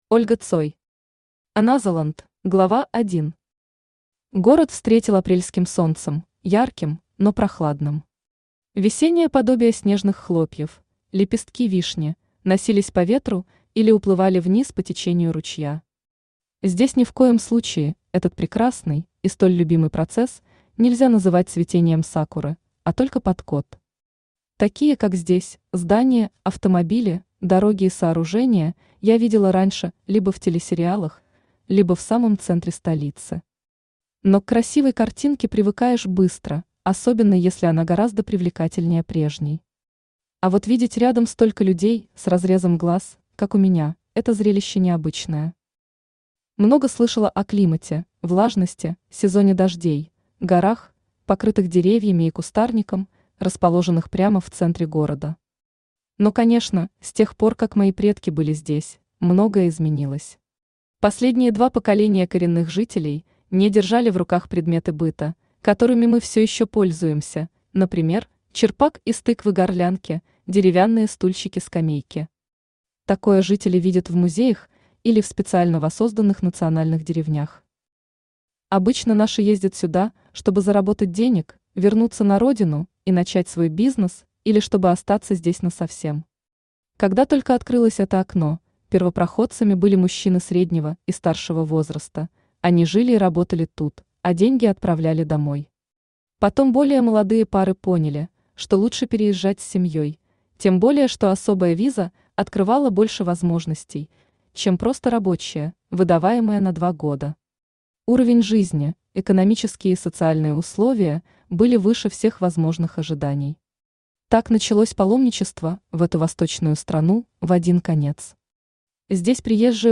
Aудиокнига Anotherland Автор Ольга Цой Читает аудиокнигу Авточтец ЛитРес.